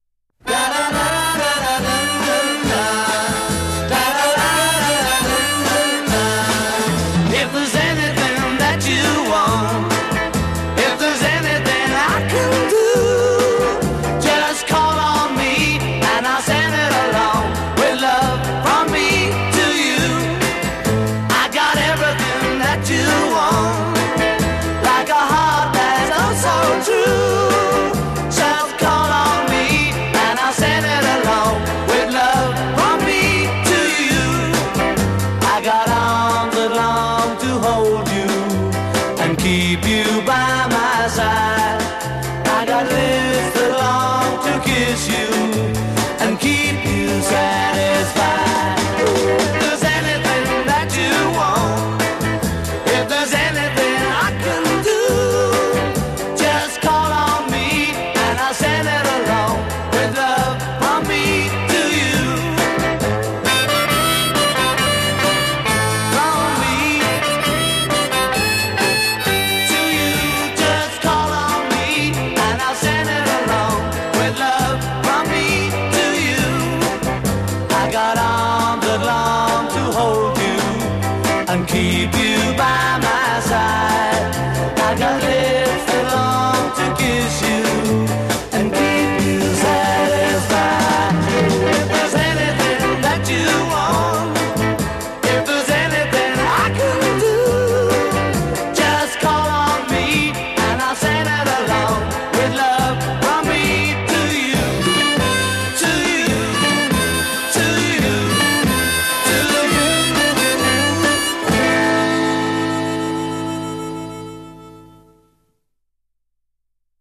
guitar, harmonica, and vocals
bass and vocals
drums
Coda   return of harmonica b'